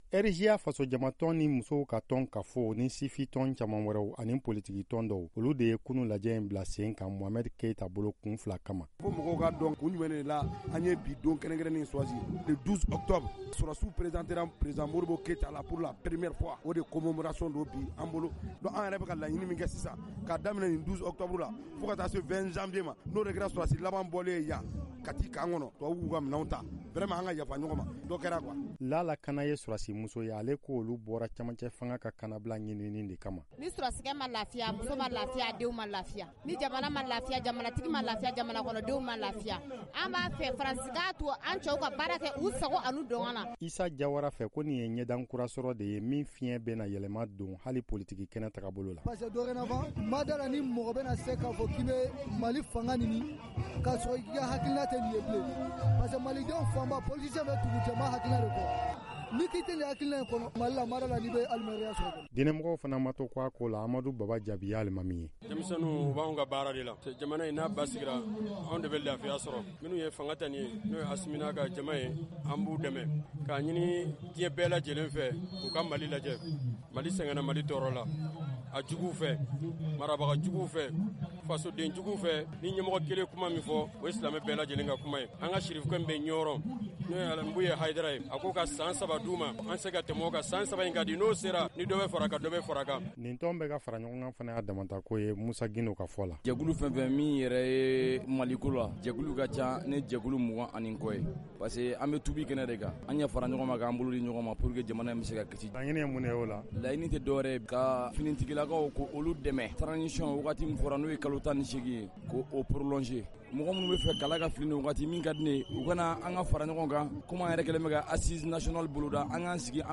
segesegeli kunnafoniw